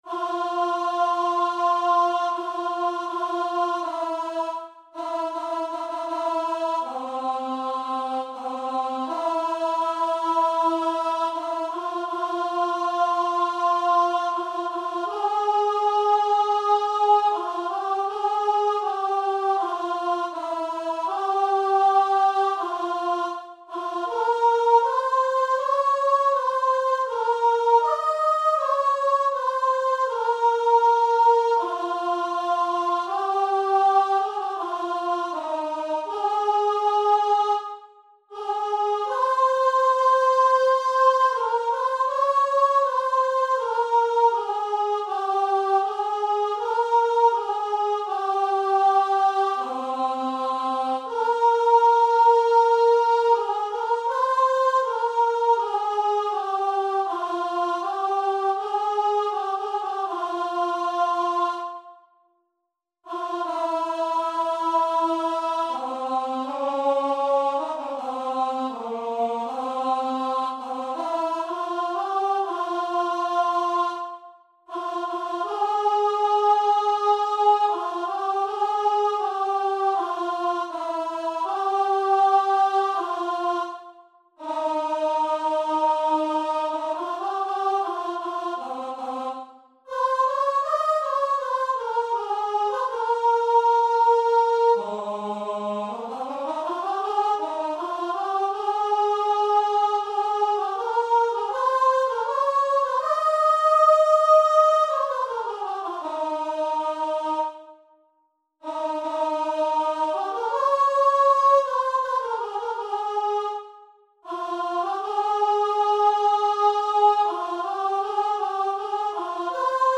4/4 (View more 4/4 Music)
Andante
Ab4-Eb6
Voice  (View more Intermediate Voice Music)
Traditional (View more Traditional Voice Music)
world (View more world Voice Music)